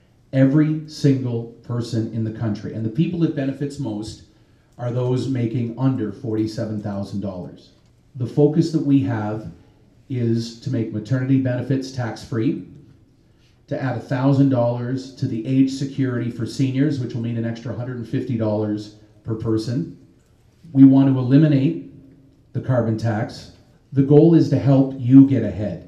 A large group of citizens at St. Joseph’s Parish was engaged in the discussion for close to two hours.